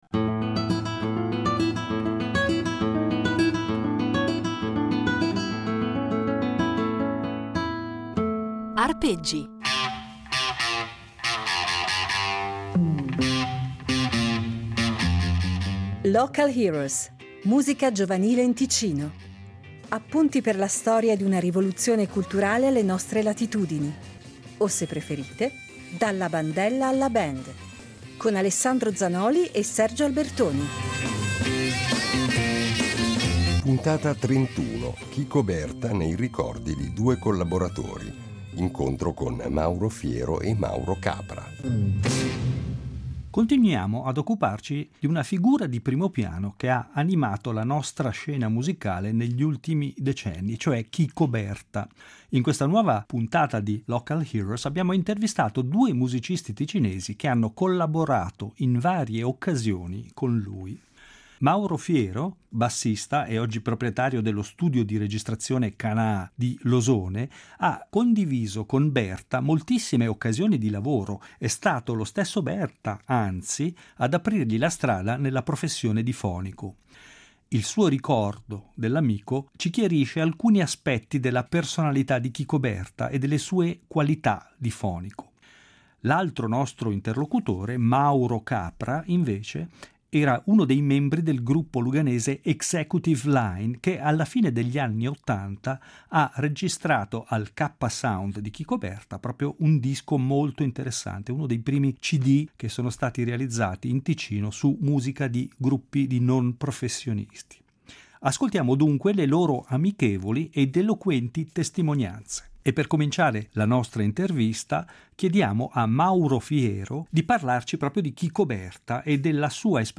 In ognuna delle puntate di “Local Heroes” affronteremo un tema diverso, e daremo la parola a un ospite diverso, ma ascolteremo anche molta musica. Musica piena di energia ed entusiasmo, che vale sicuramente la pena di far risuonare, a distanza di decenni.